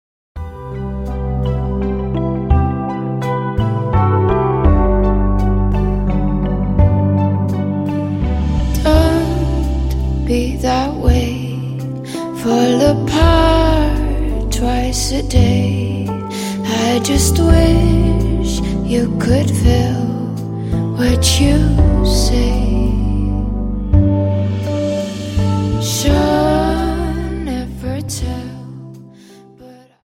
Slow Waltz 29 Song